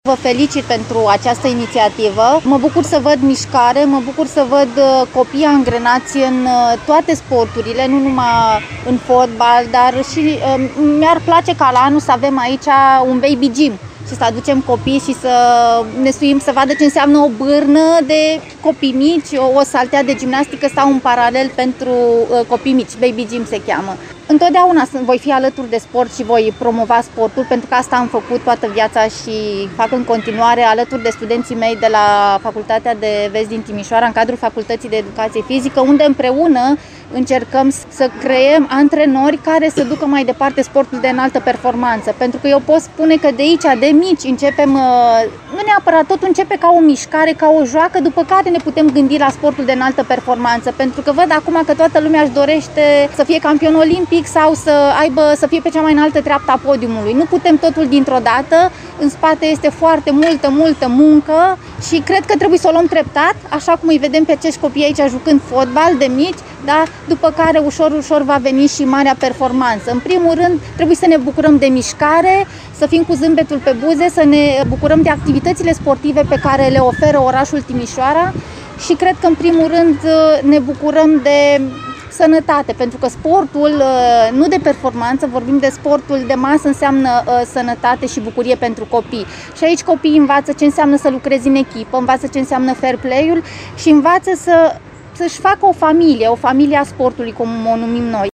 Fosta campioană mondială și olimpică, gimnasta Simona Amânar-Tabără, a participat la eveniment: